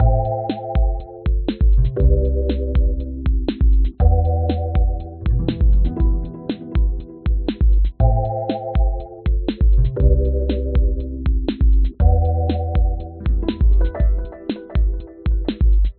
最小的法式电子环路
描述：一个简约的电子环路：鼓、风琴和弦、指法直立式贝司、指法直立式贝司琶音。
Tag: 电子 法式触摸 循环 极简主义